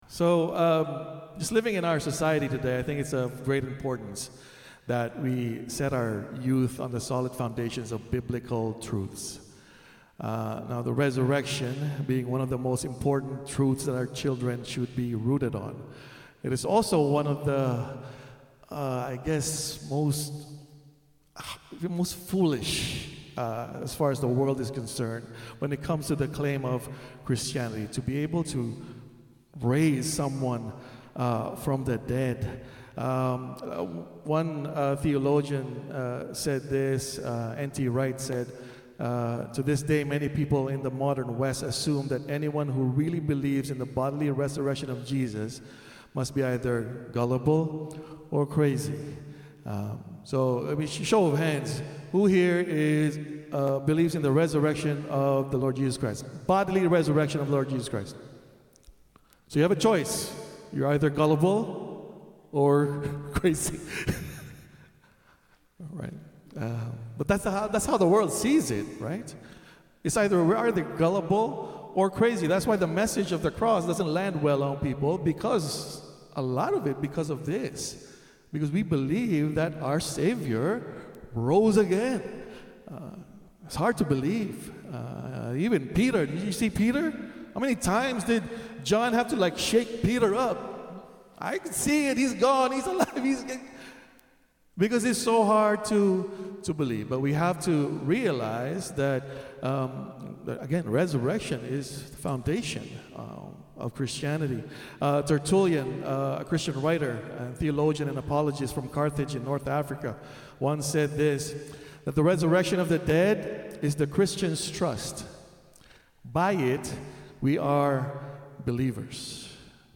In a short message following our Youth Ministry Easter performance